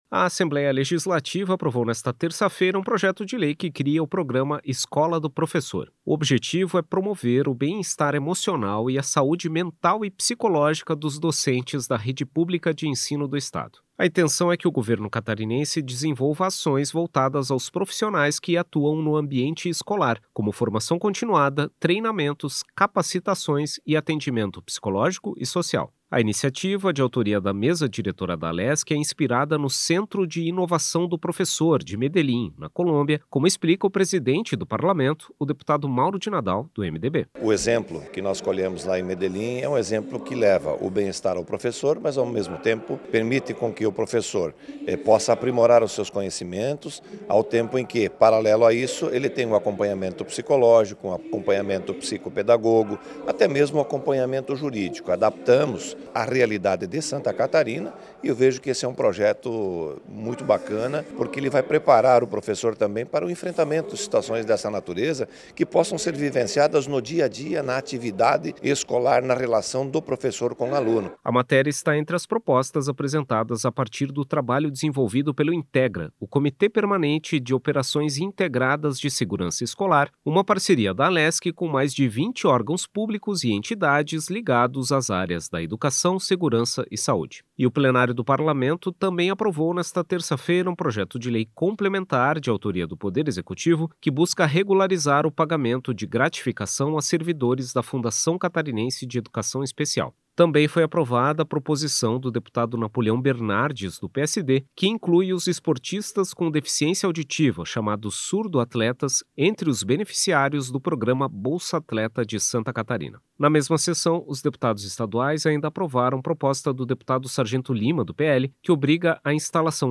Entrevista com:
- deputado Mauro de Nadal (MDB), presidente da Assembleia Legislativa.